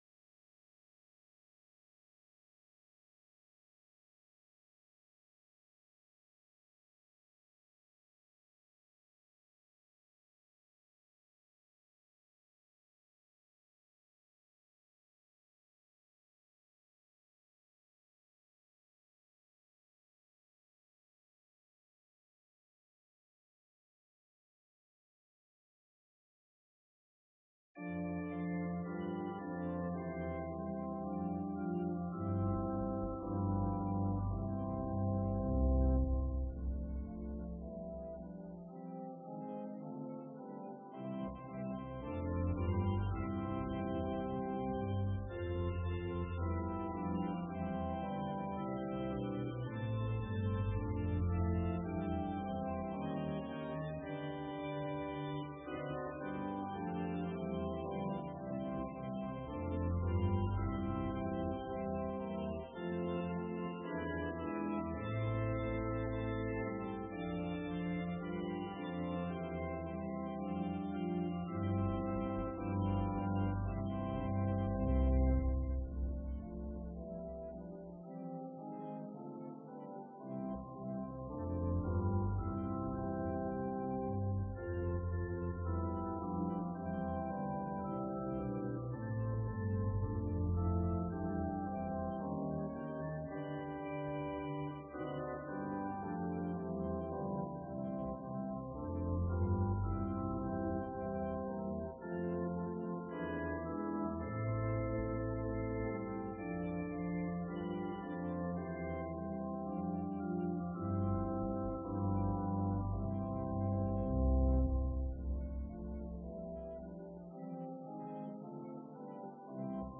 Sermon:How Do You Use Your Tongue?